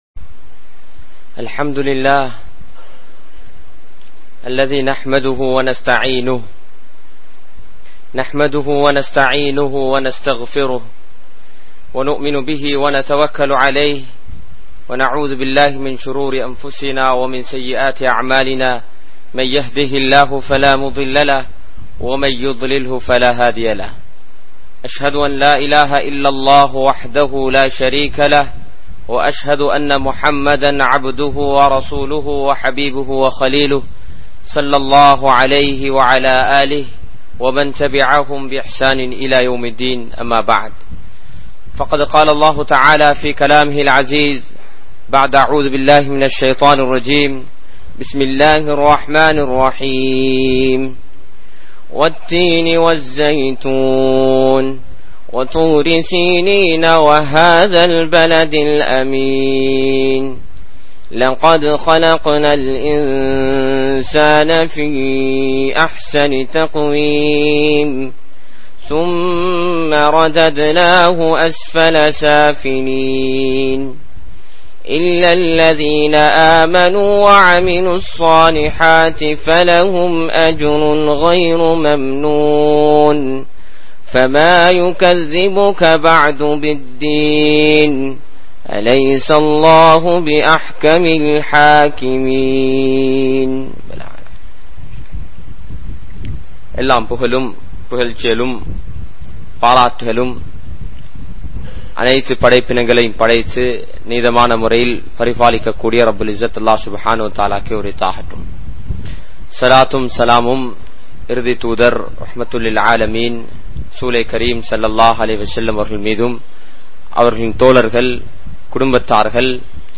Aasihalaik Kattup Paduthugal(ஆசைகளைக் கட்டுப்படுத்துங்கள்) | Audio Bayans | All Ceylon Muslim Youth Community | Addalaichenai